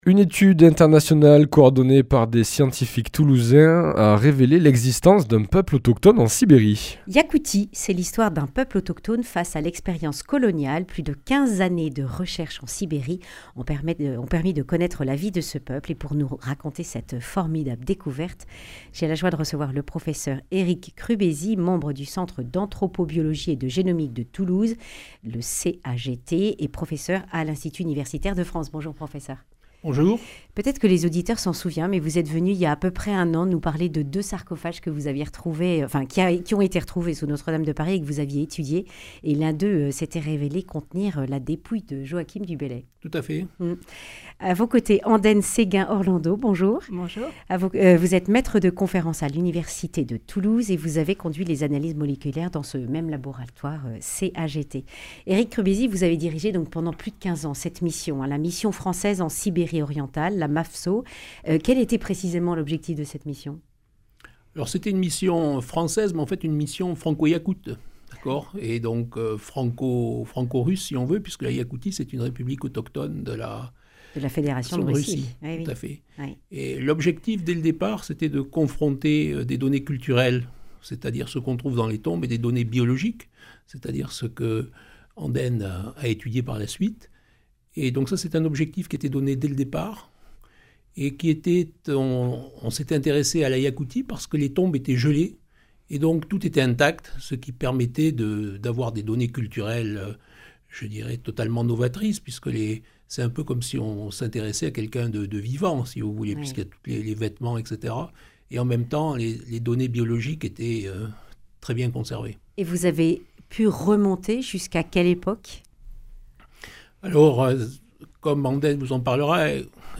Accueil \ Emissions \ Information \ Régionale \ Le grand entretien \ Des chercheurs toulousains découvrent Yacoutie, l’histoire d’un peuple (…)